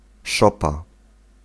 Ääntäminen
IPA: /loːts/